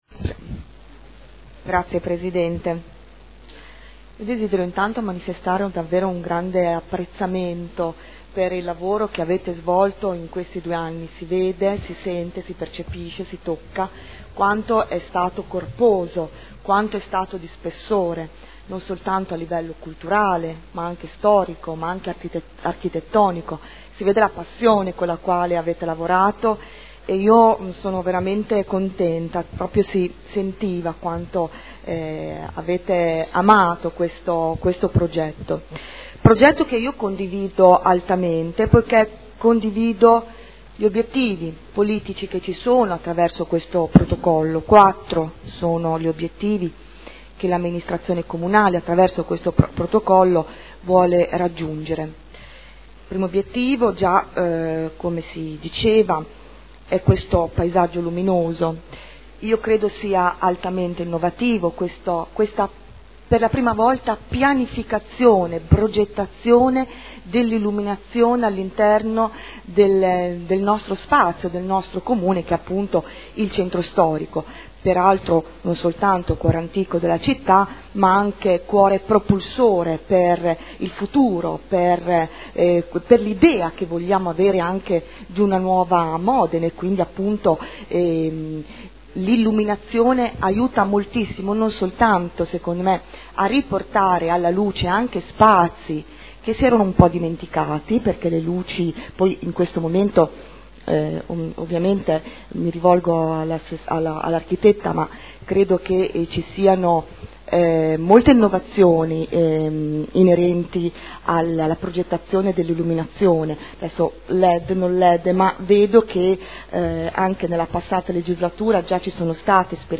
Ingrid Caporioni — Sito Audio Consiglio Comunale